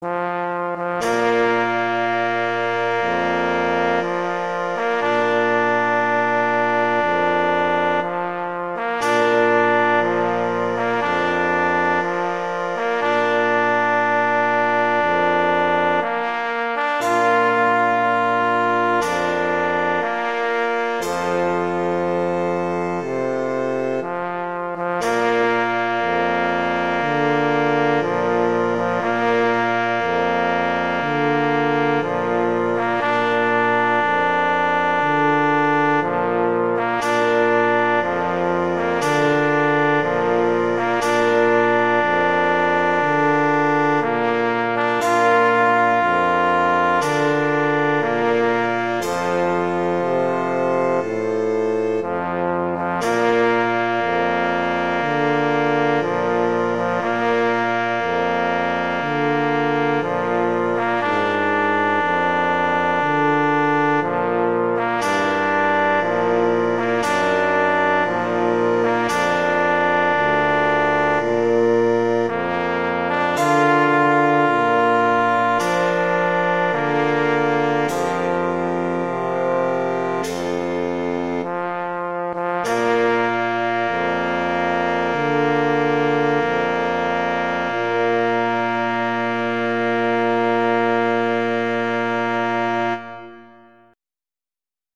arrangements for brass quintet